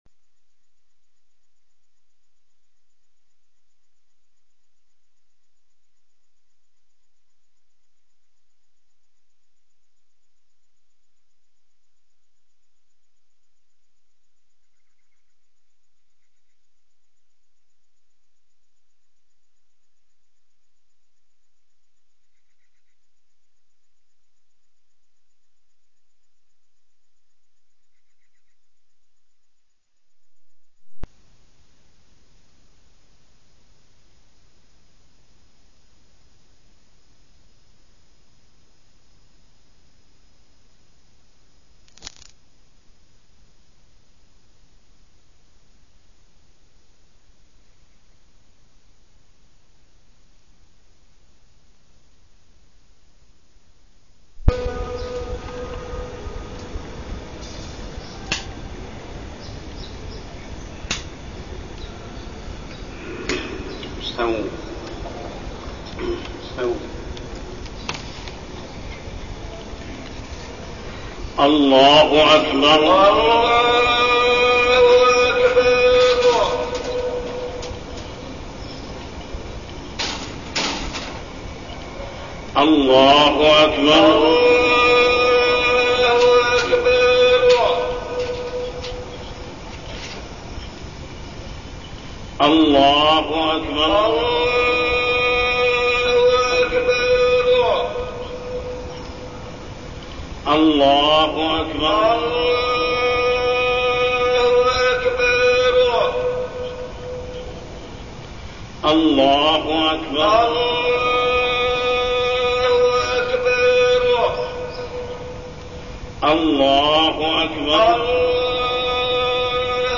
تاريخ النشر ١١ رجب ١٤٠٩ هـ المكان: المسجد الحرام الشيخ: محمد بن عبد الله السبيل محمد بن عبد الله السبيل صلة الرحم